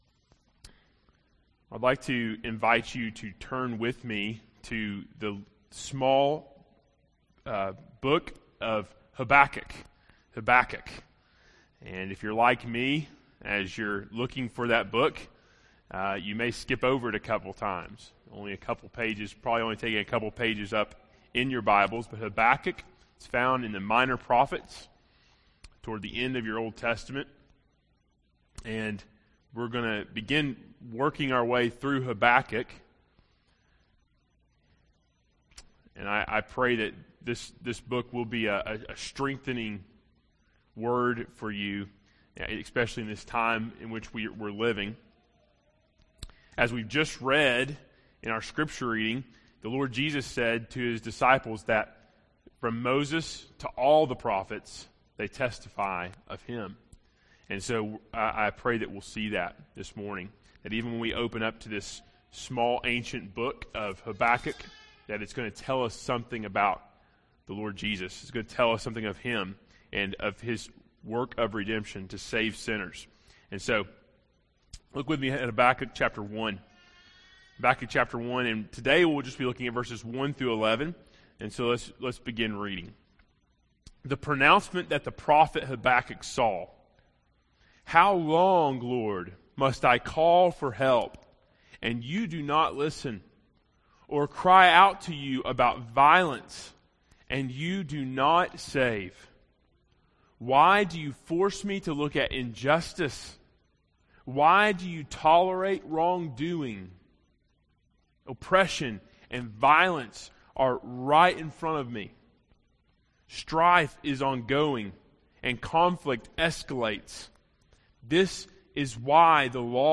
Preaching through Habakkuk – Plainfield Baptist Church